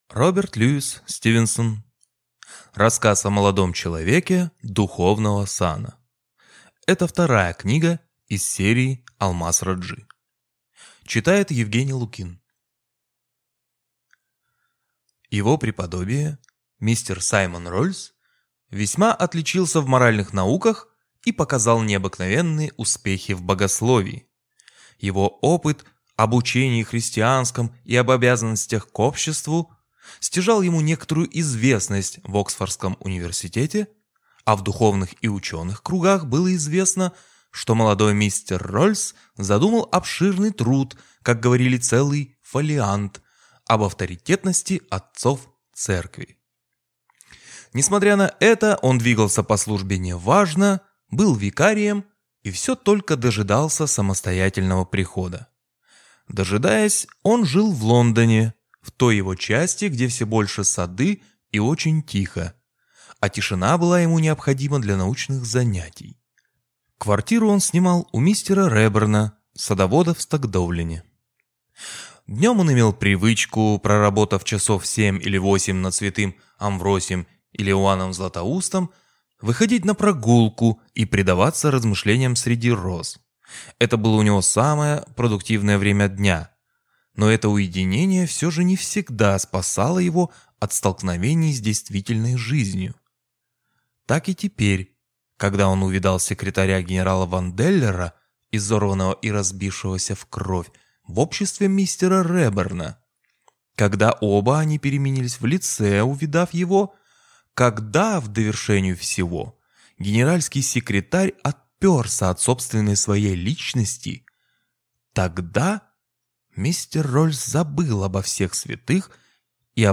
Аудиокнига Рассказ о молодом человеке духовного сана | Библиотека аудиокниг